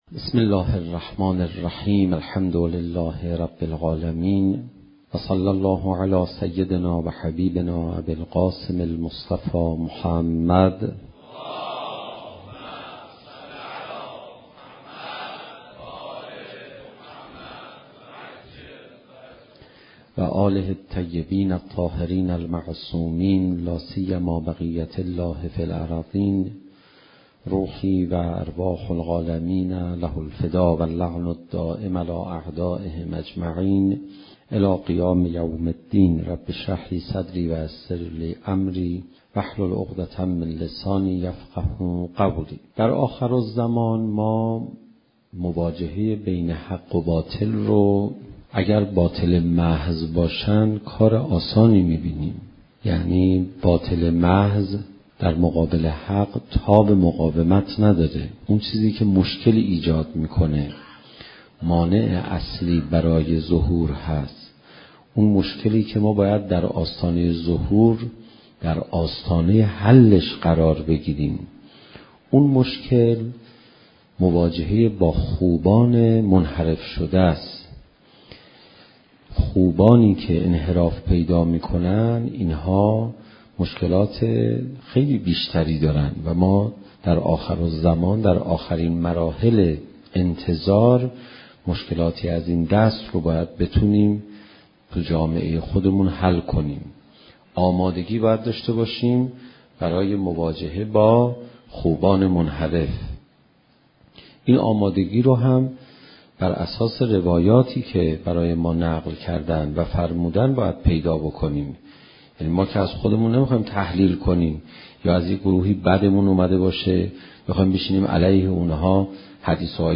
زمان: 40:11 | حجم: 9.50 MB | تاریخ: 1395 | مکان: حسینیة آیت الله حق شناس